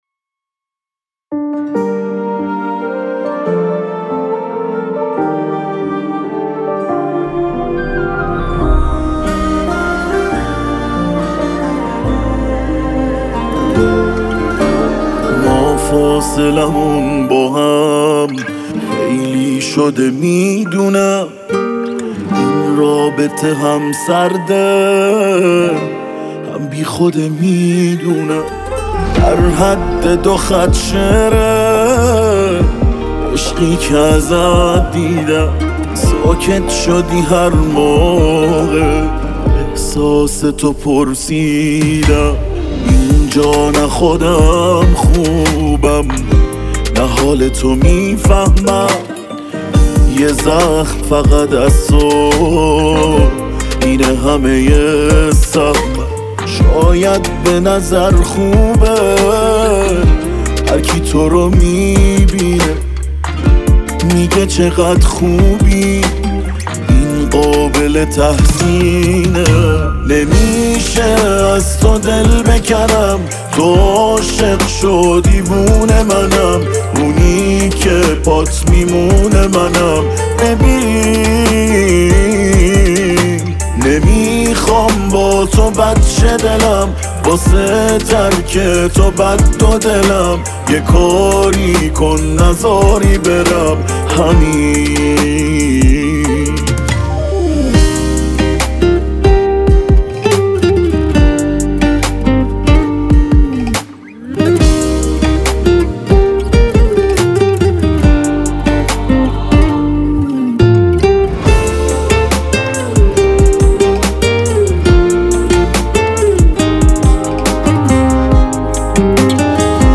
عاشقانه غمگین